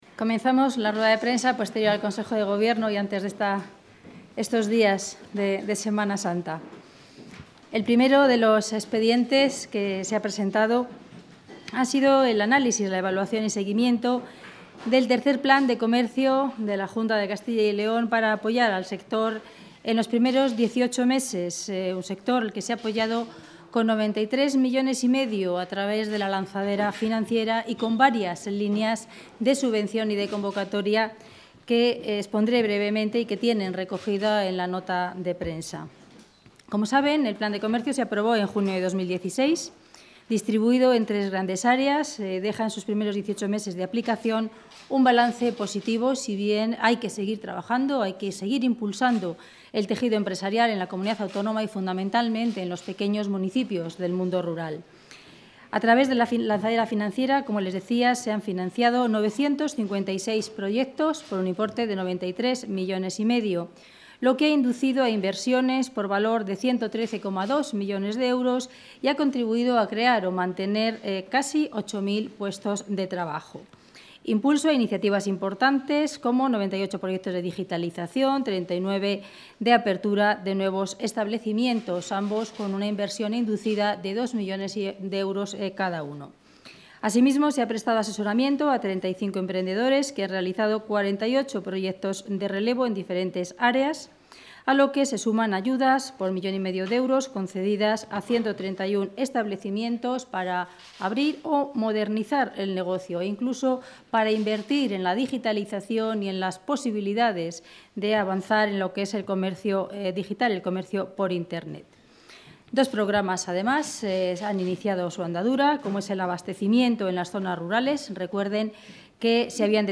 Rueda de prensa posterior al Consejo de Gobierno.